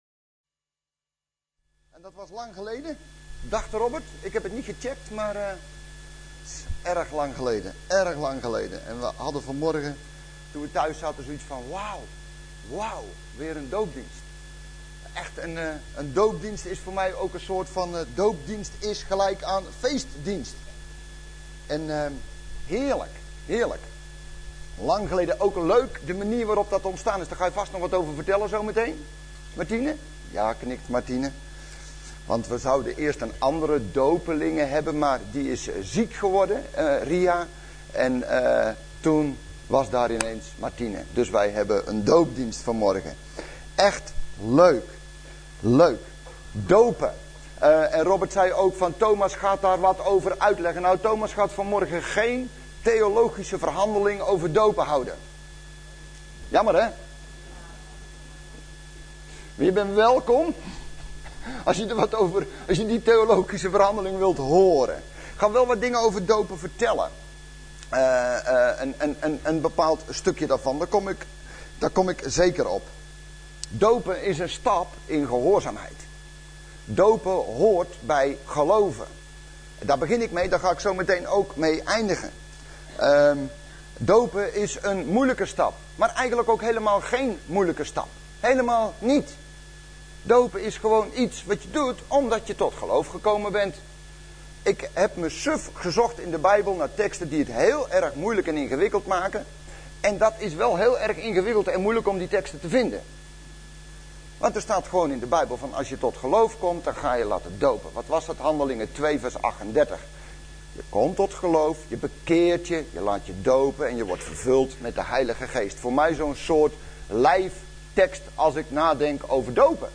Overzicht van preken van Preken op Christengemeente Bethel
Doopdienst